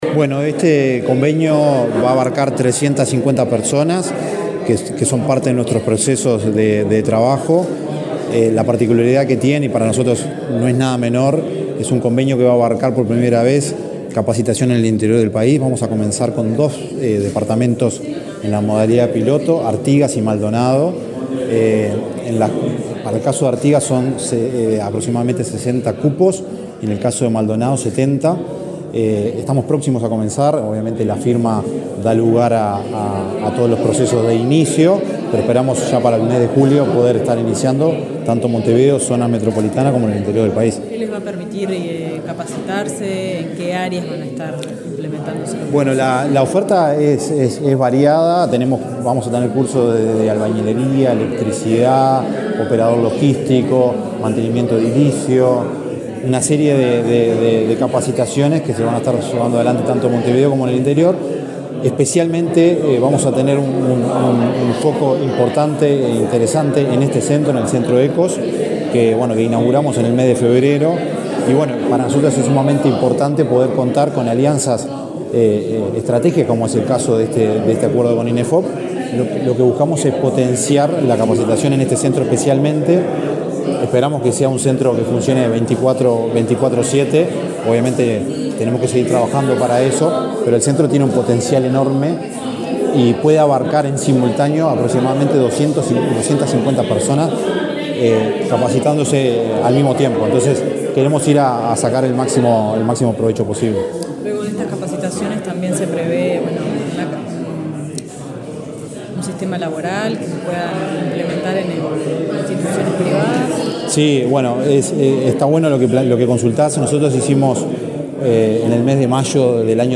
Declaraciones del coordinador general de la Dinali, Daniel Fernández
El Ministerio de Desarrollo Social, a través de la Dirección Nacional de Apoyo al Liberado (Dinali), firmó un convenio con el Instituto Nacional de Empleo y Formación Profesional (Inefop), para proveer cursos y capacitaciones en oficios a personas que recuperaron su libertad. El coordinador general de la Dinali, Daniel Fernández, explicó a la prensa el alcance del acuerdo.